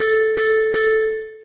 Techmino/media/effect/chiptune/warn_2.ogg at fac020db8ca5c3063fcca1ef07fc07f021c446ec
warn_2.ogg